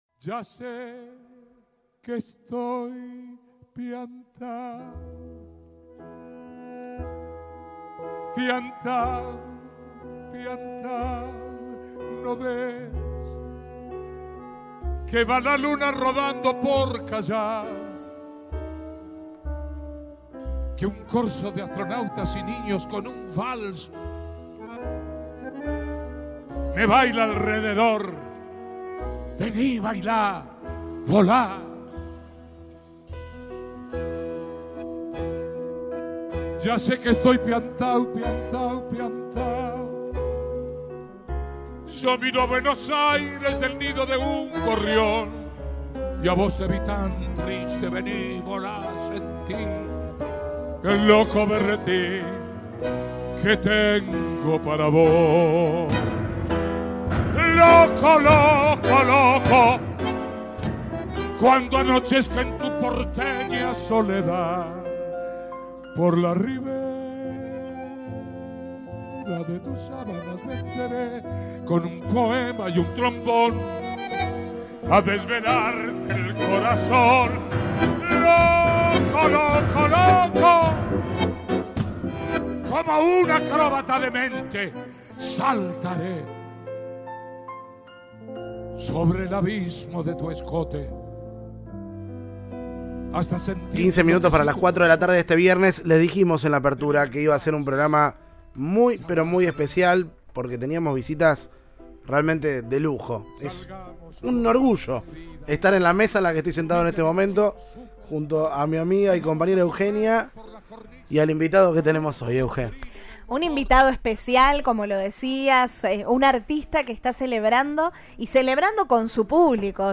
El cantante y actor estuvo en Cadena 3 Rosario en la previa de su show en la ciudad. En una charla emotiva, rica en memorables anécdotas, dejó un adelanto y se permitió reflexiones profundas.